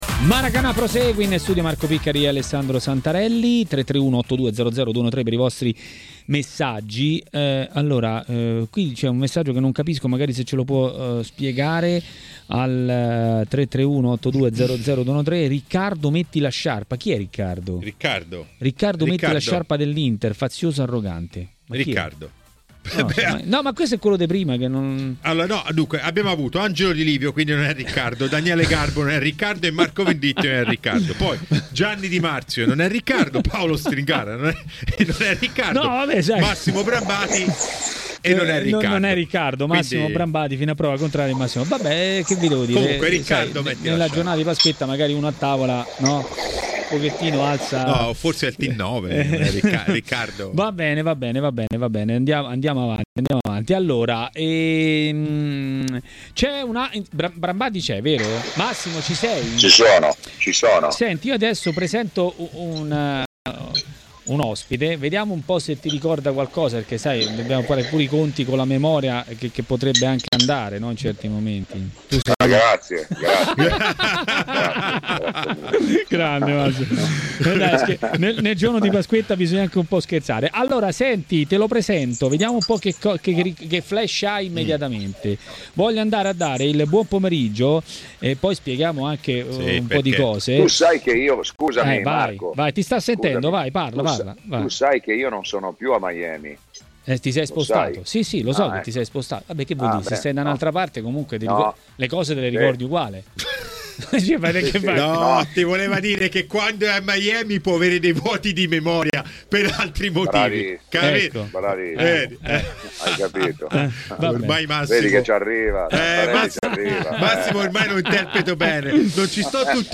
In studio
© registrazione di TMW Radio